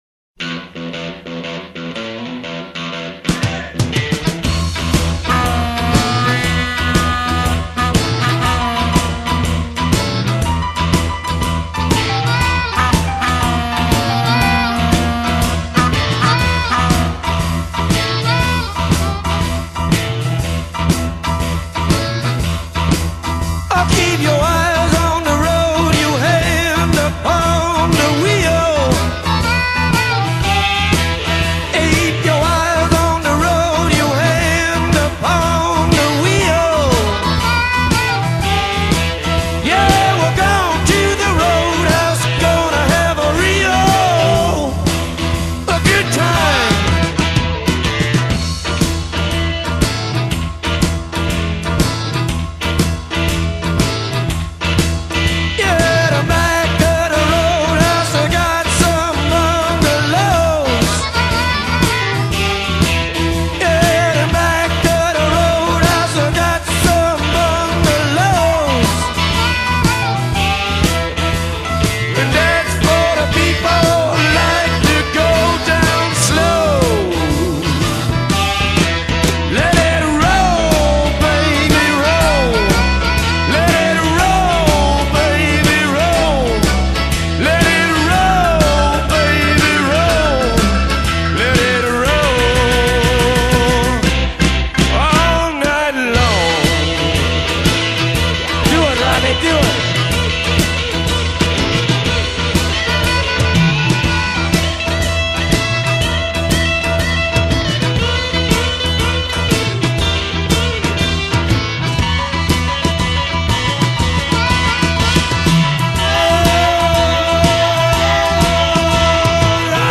Blues Rock, Psychedelic Rock